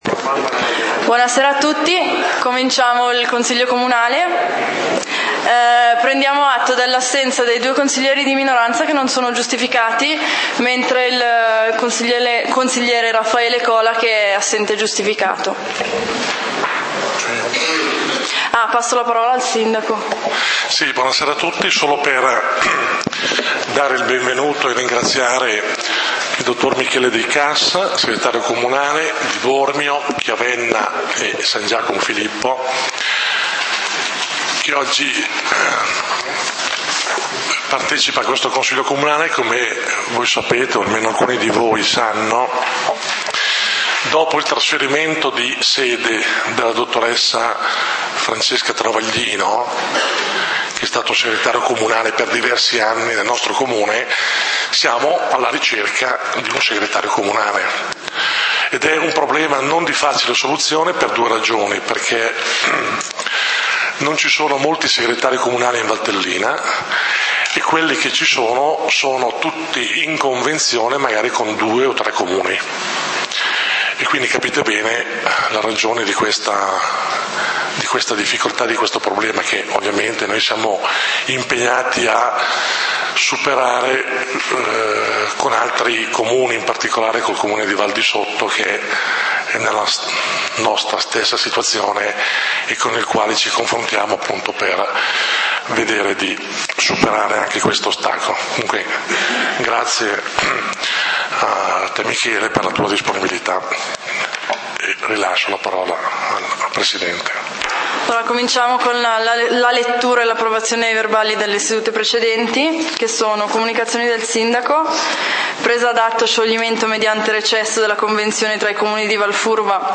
Consiglio comunale di Valdidentro del 27 Novembre 2013